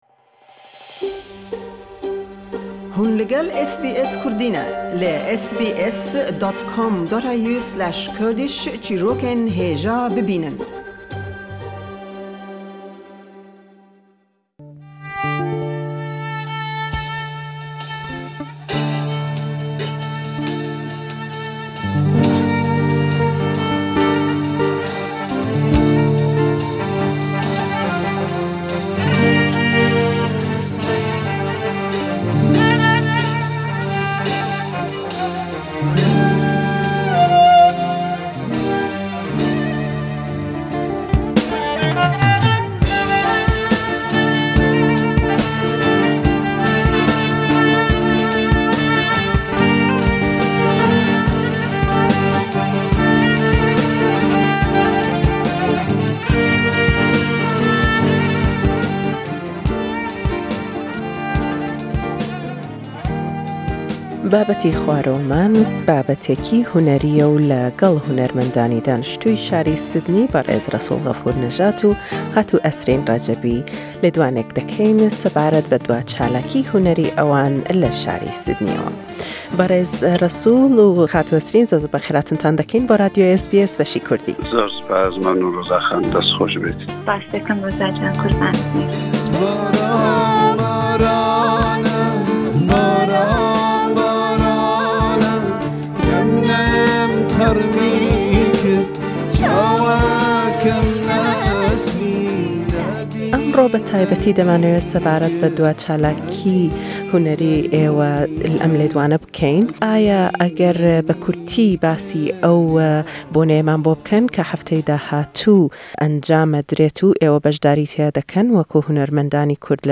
Le em lêdwane le gell hunermandanî goranîbêjî şarî Sydney